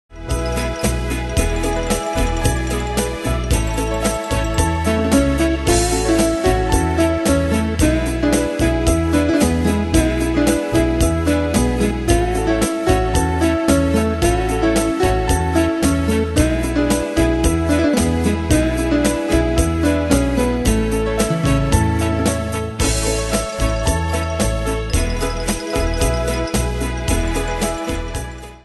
Style: Retro Ane/Year: 1958 Tempo: 112 Durée/Time: 3.47
Danse/Dance: Rhumba Cat Id.
Pro Backing Tracks